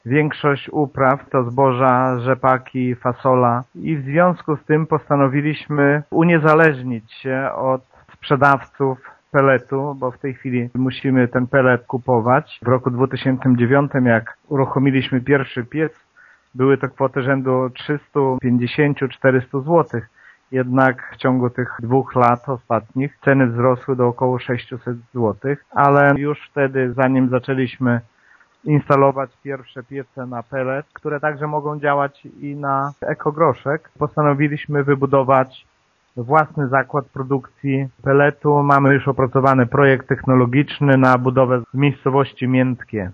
„Pelet jest bowiem produkowany ze słomy zbóż i odpadów drzewnych takich jak wióry czy trociny, a w związku z tym, że jesteśmy regionem typowo rolniczym takie surowce mamy właściwie w zasięgu ręki” - przekonuje wójt Lech Szopiński: